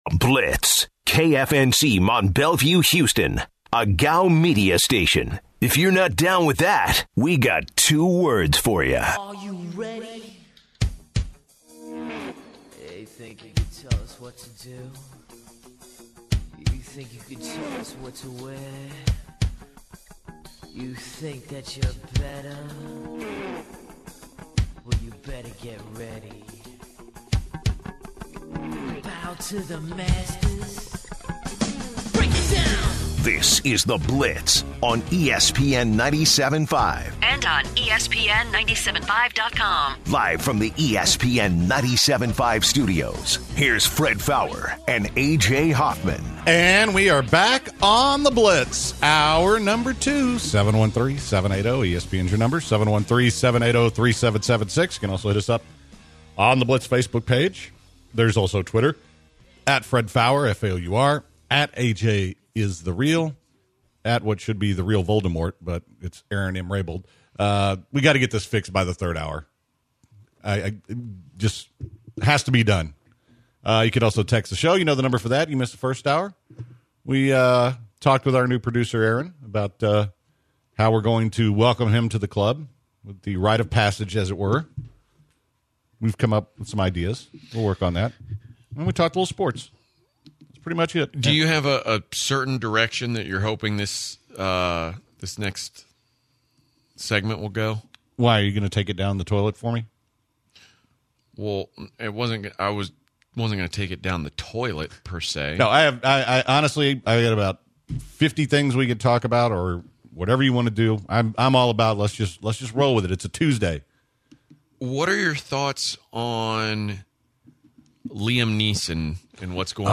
The guys get the second hour of the show rolling with a conversation about a highly controversial statement made 40 years ago by Liam Neeson that was recently unearthed, which turns into a conversation about race and racism that plenty of callers wanted to chime in on.